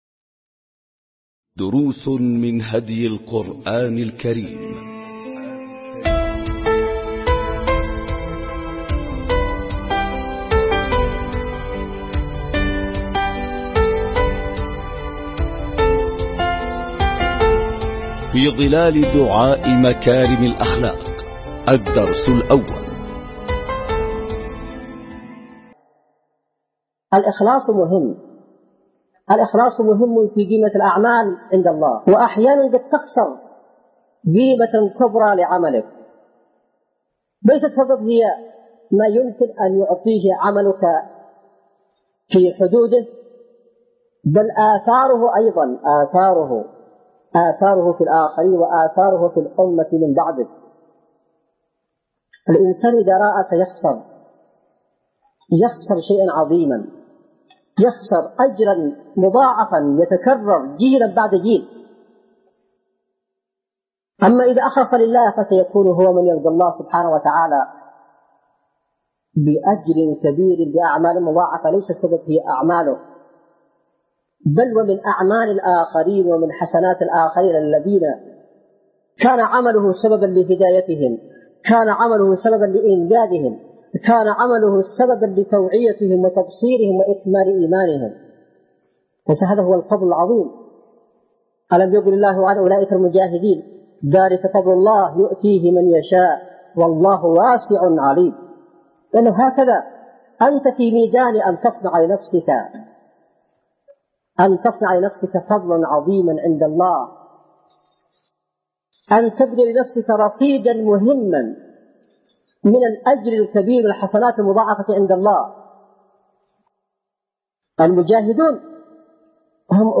🟢دروس من هدي القرآن الكريم 🔹في ظلال دعاء مكارم الأخلاق – الدرس الأول🔹 ملزمة الأسبوع | اليوم الخامس ألقاها السيد / حسين بدرالدين الحوثي بتاريخ 4/2/2002م | اليمن – صعدة